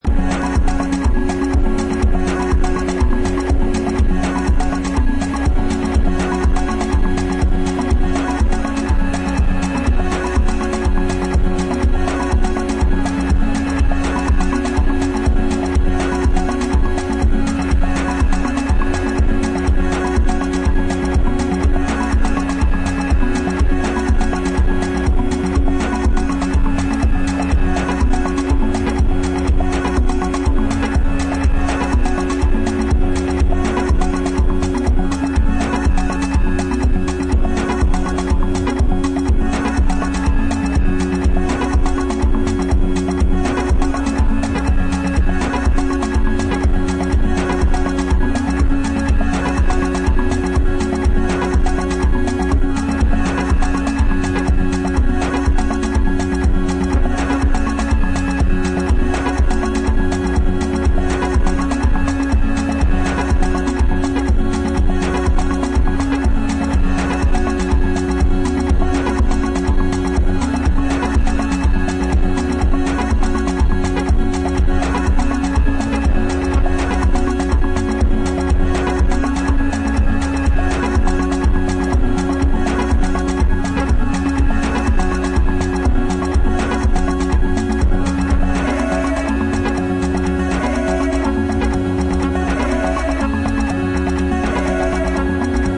supplier of essential dance music
Electronix Indie Ambient Pop